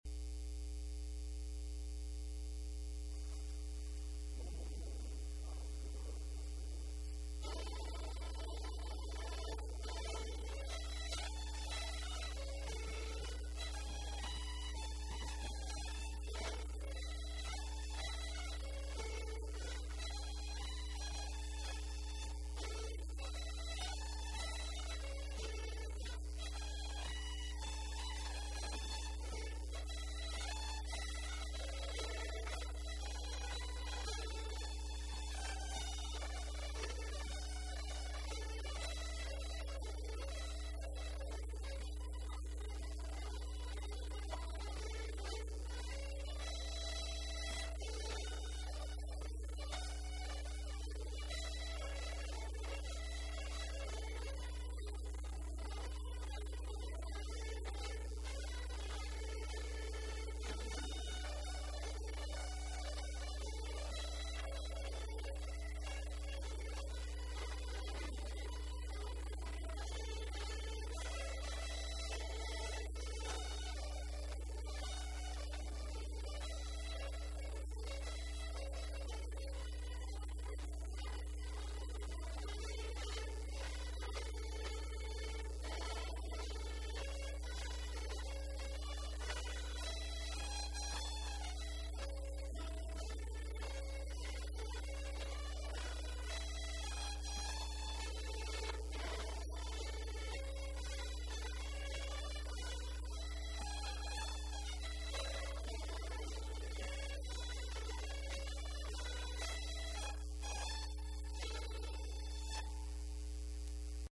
Hambo – 2